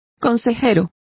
Complete with pronunciation of the translation of guides.